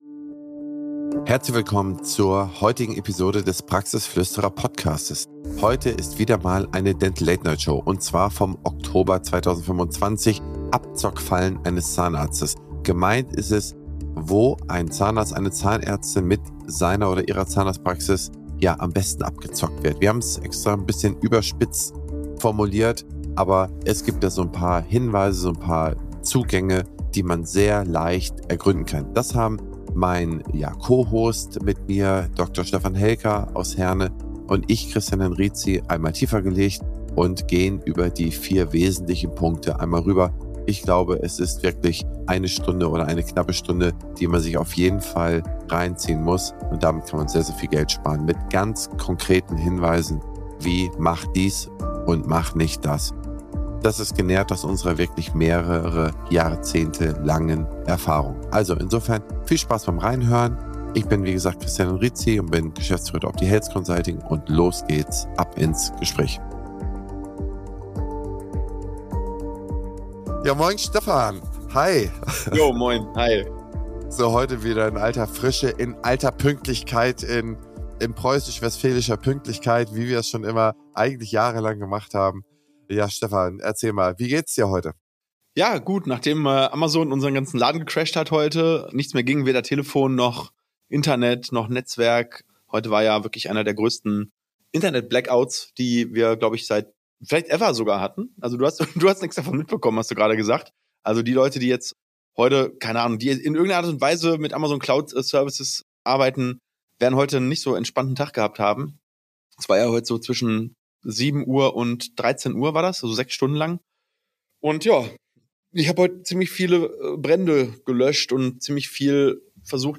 einer Aufzeichnung der Dental Late Night Show